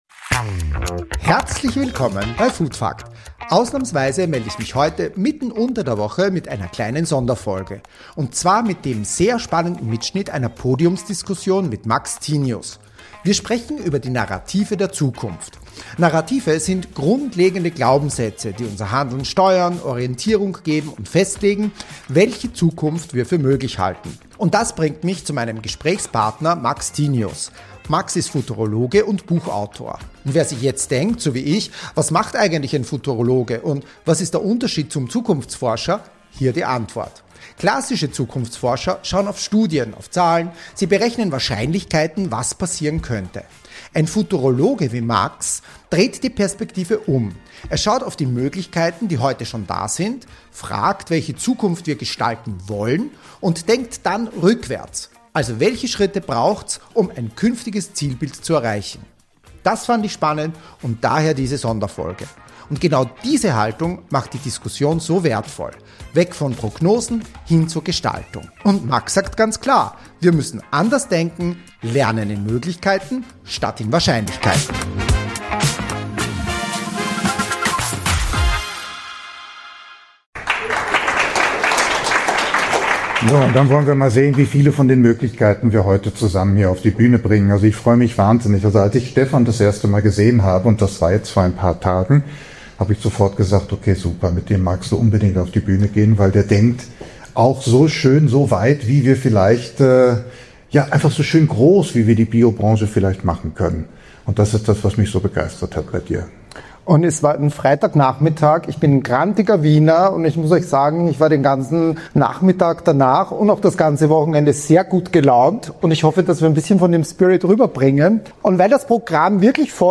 Beschreibung vor 1 Monat Welche Narrative braucht die Bio-Branche in Zukunft? In dieser Sonderfolge von Food Fak(t) hört ihr den Mitschnitt einer Podiumsdiskussion im Rahmen der Biofach Messe Nürnberg – organisiert von Modem Conclusa.